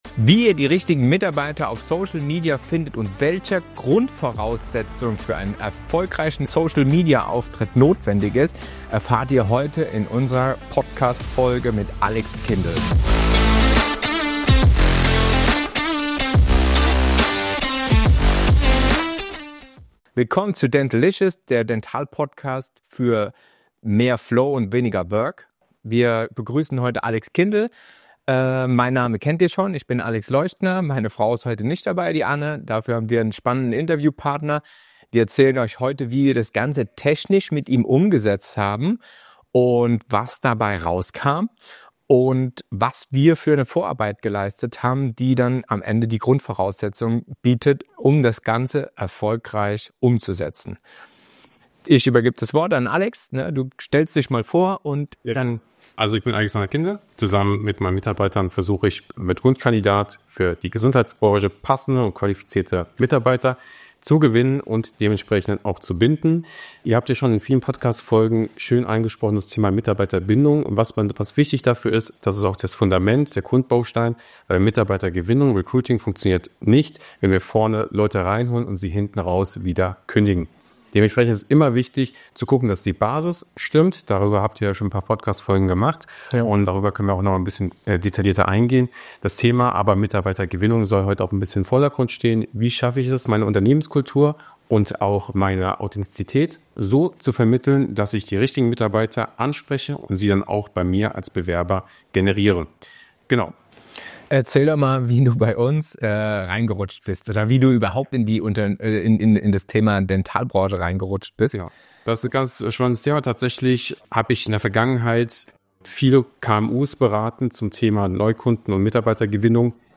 Nr. 13 - Mitarbeitergewinnung für Zahnärzte & Labore: Dein ganzheitliches Konzept - Interview